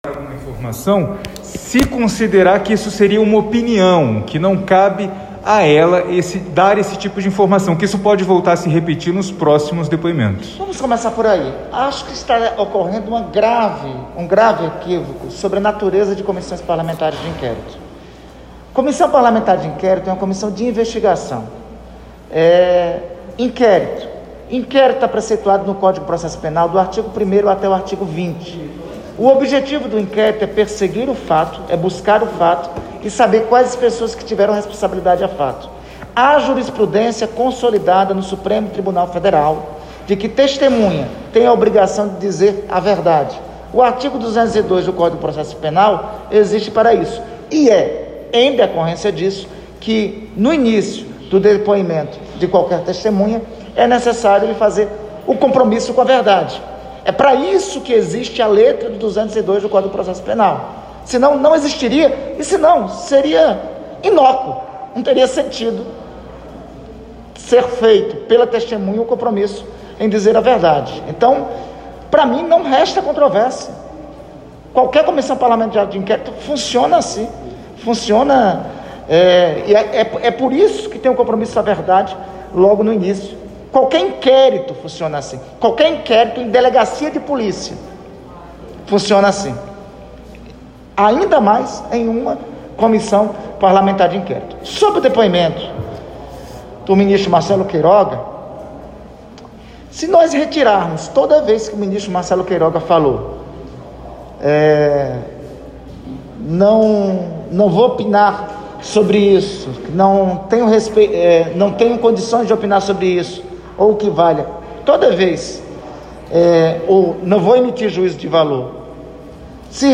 Íntegra da entrevista coletiva com Randolfe Rodrigues
Acompanhe a íntegra da entrevista coletiva concedida pelo senador Randolfe Rodrigues (Rede-AP), vice-presidente da CPI da Pandemia, após o depoimento do ministro da Saúde, Marcelo Queiroga.